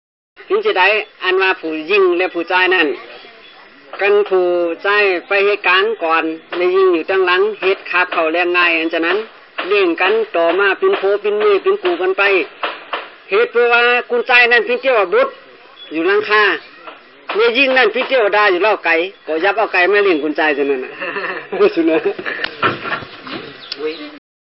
Disable Ginger in this text field Disable Ginger on this website × ขอบเขตและสื่อ : เทปรีล